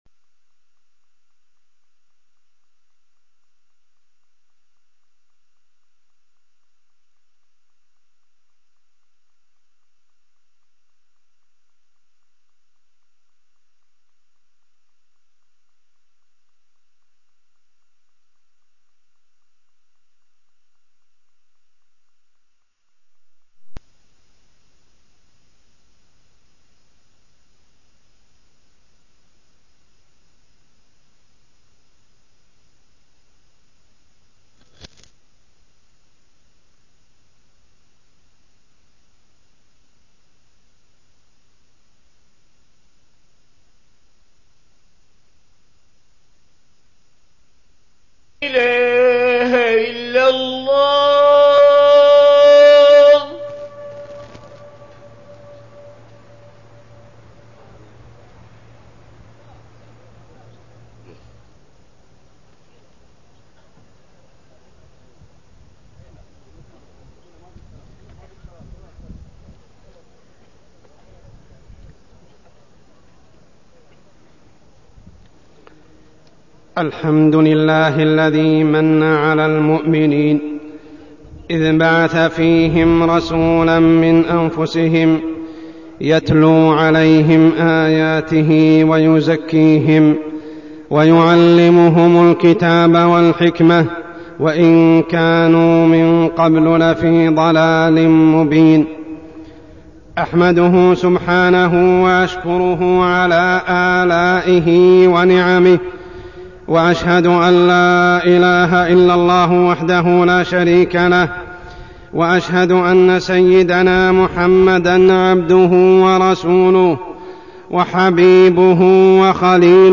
تاريخ النشر ٧ ربيع الأول ١٤١٨ هـ المكان: المسجد الحرام الشيخ: عمر السبيل عمر السبيل بعثة النبي صلى الله عليه وسلم